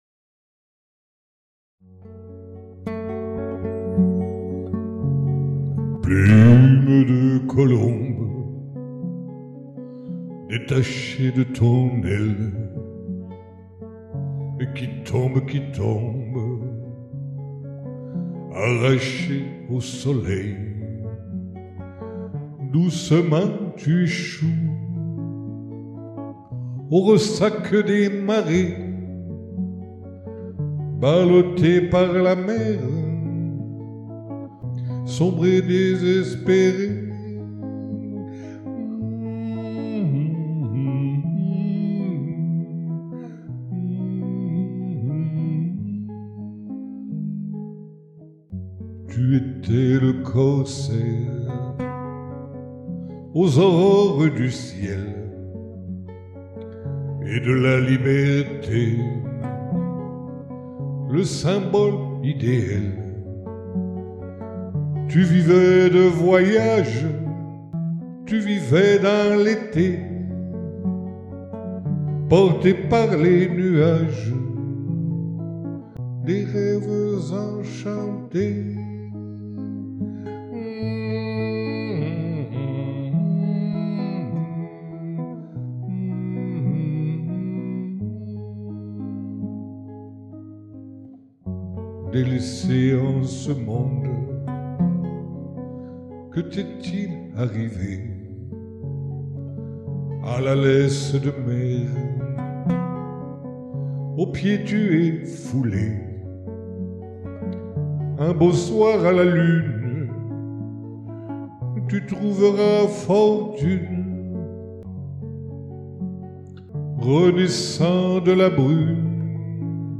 [Capo 2°] (Adaptations) 30 déc. 2020